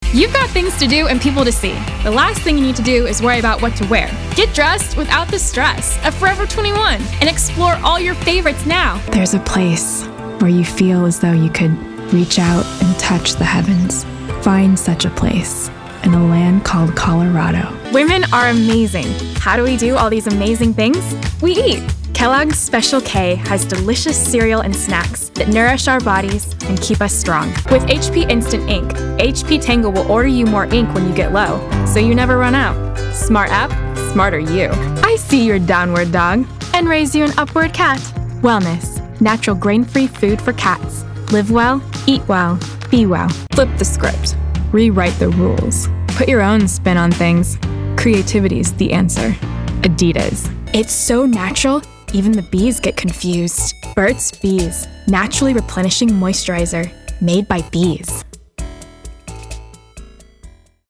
Voix off
Commercial Démo Américaine
18 - 35 ans - Mezzo-soprano